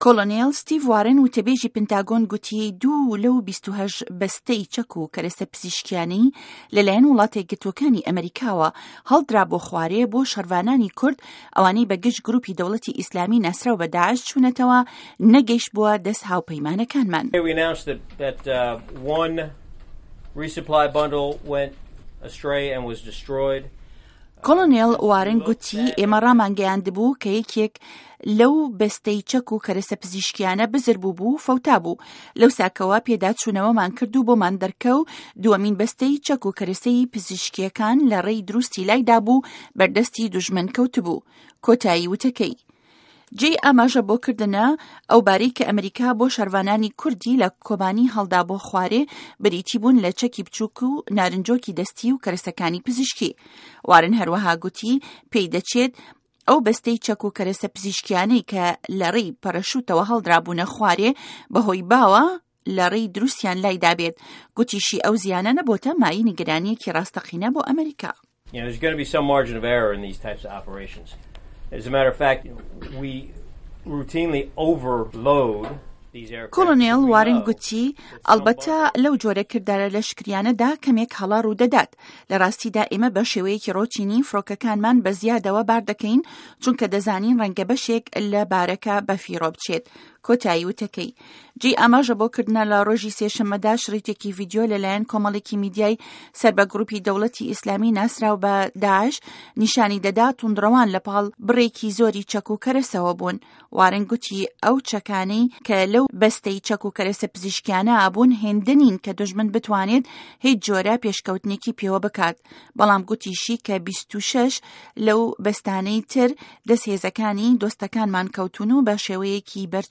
ده‌قی ڕاپـۆرتی کۆبانی - داعش